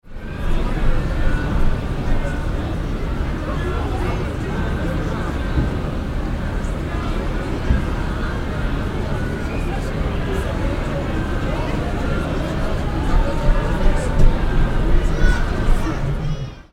Pre-Concert Crowd Ambience Sound Effect
Pre-concert-crowd-ambience-sound-effect.mp3